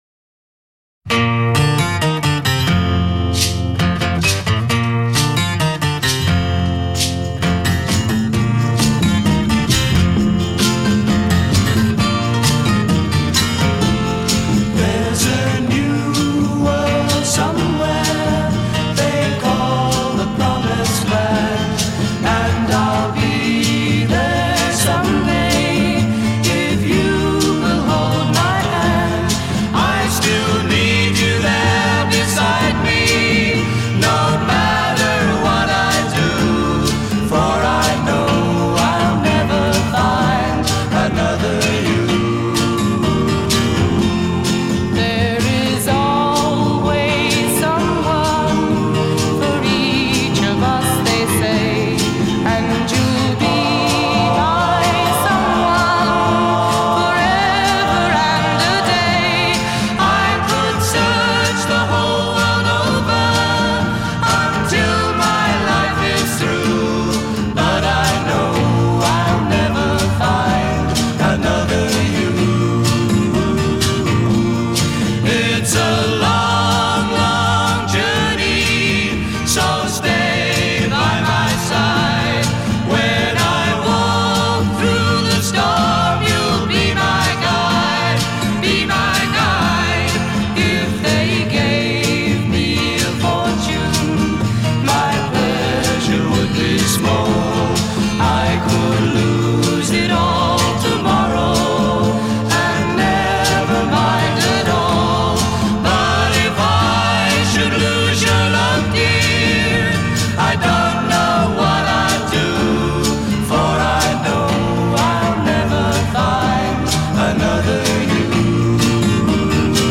Australian folk-pop group